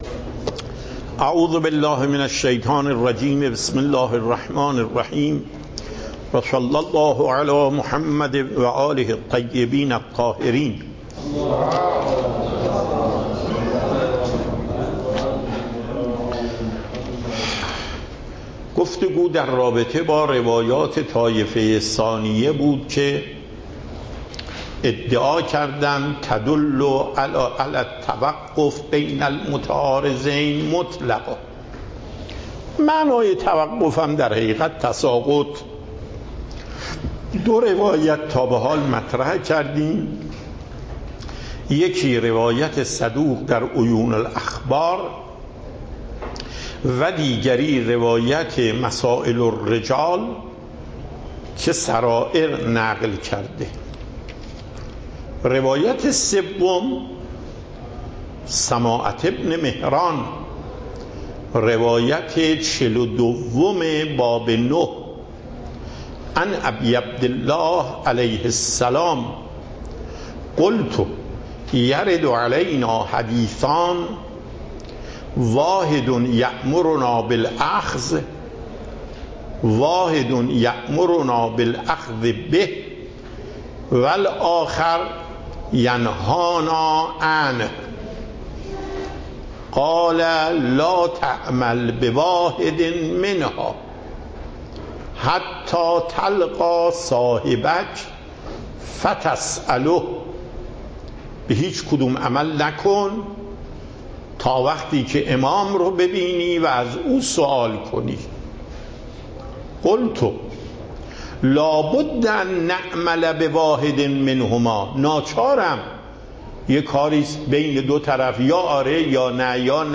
آیت الله سید علی محقق داماد
صوت و تقریر درس